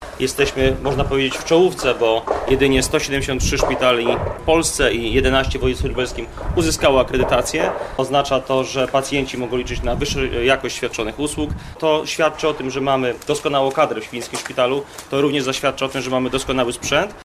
– To potwierdzenie wysokiej jakości świadczonych usług i bezpieczeństwa pracowników – mówi starosta Łukasz Reszka.